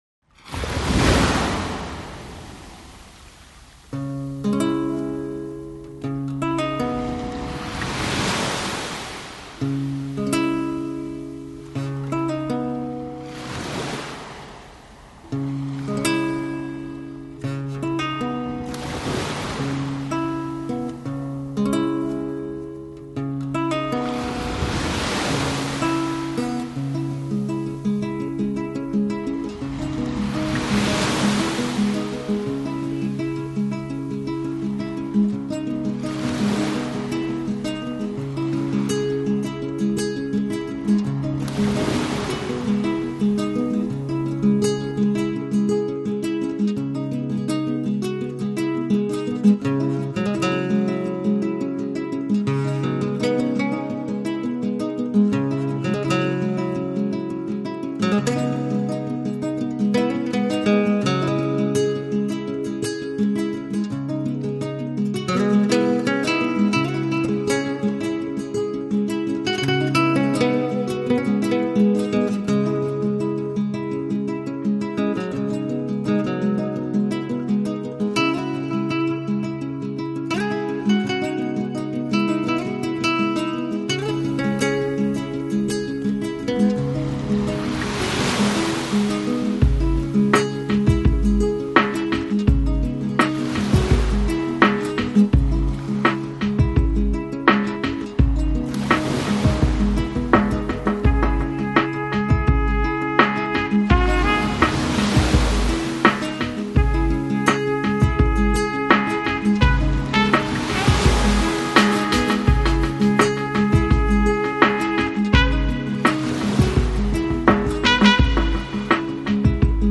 Lounge, Downtempo, Chill Out